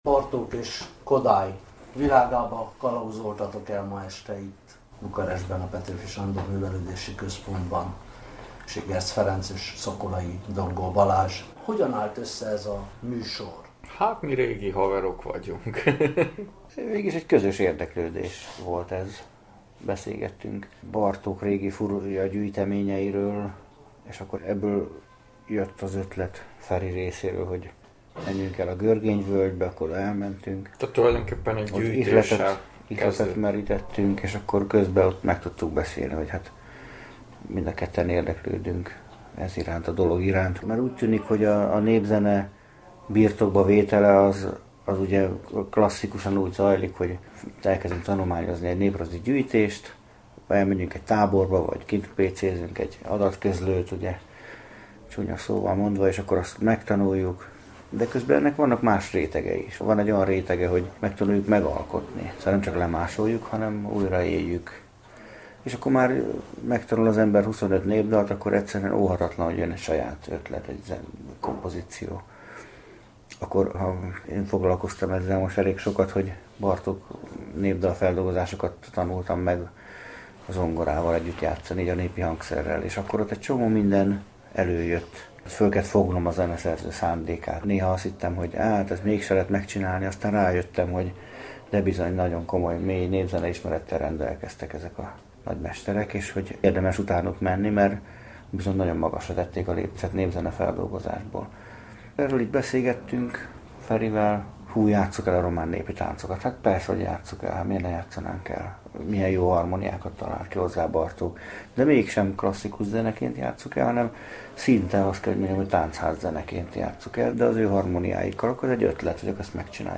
Rendhagyó koncert résztvevői lehettünk a Bukaresti Magyar Napokon a Petőfi Művelődési Központban.